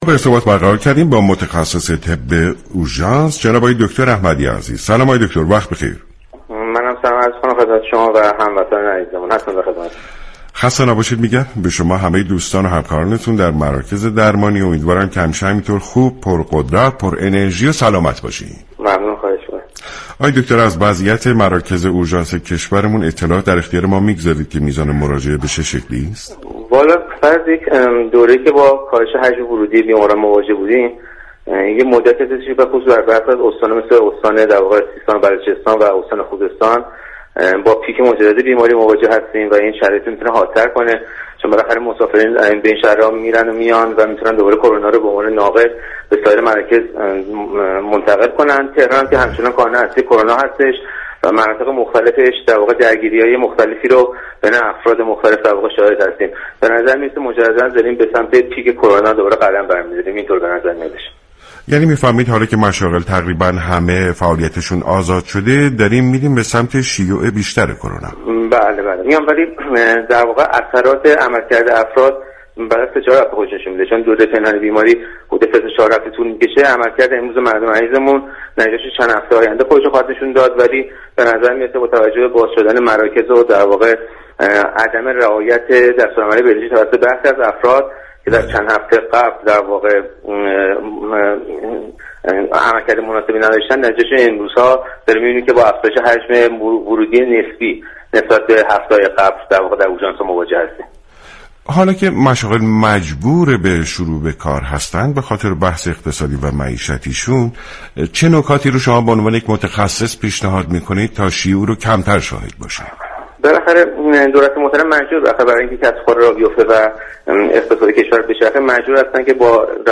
شما می توانید از طریق فایل صوتی پیوست شنونده بخشی از برنامه سلامت باشیم رادیو ورزش كه شامل صحبت های این متخصص طب اورژانس درباره كرونا و پاسخگویی به سوالات عموم است؛ باشید.